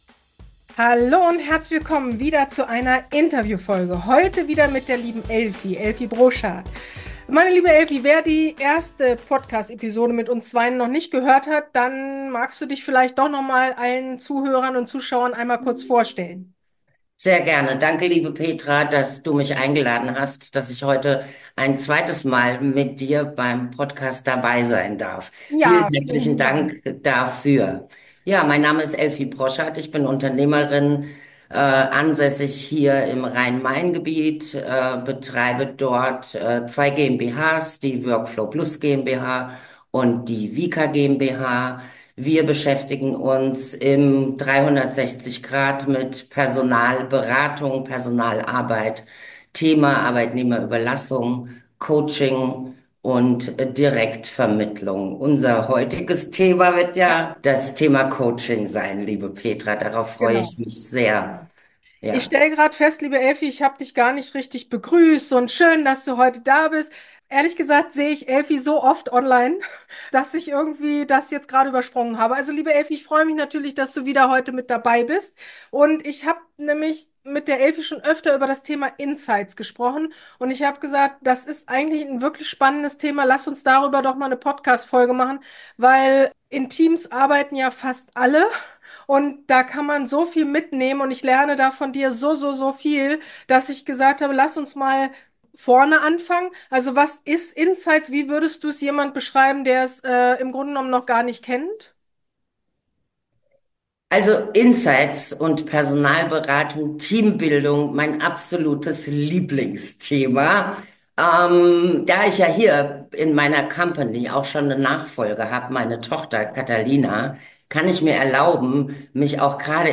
akQuise strategen - Interview Podcast